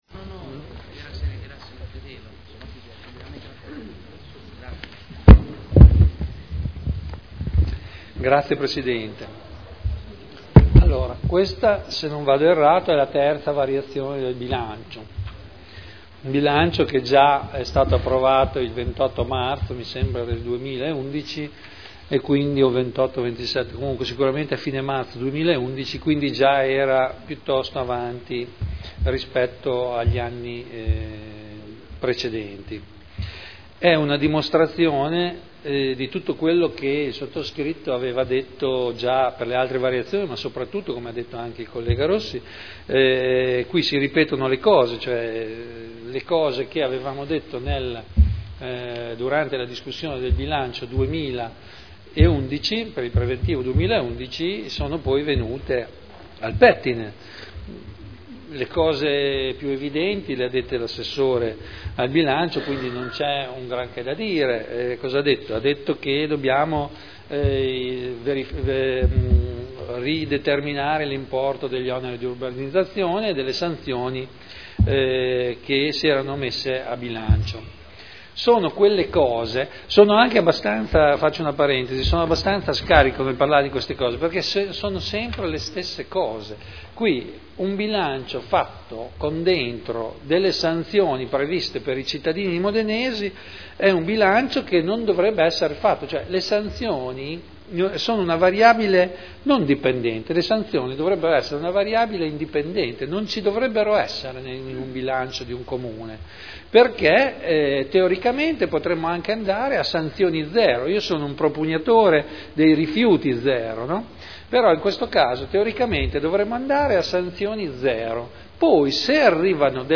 Vittorio Ballestrazzi — Sito Audio Consiglio Comunale
Seduta del 28 novembre Proposta di deliberazione Bilancio di previsione 2011 - Bilancio pluriennale 2011-2013 - Programma triennale dei lavori pubblici 2011-2013 - Assestamento - Variazione di bilancio n. 3 Dibattito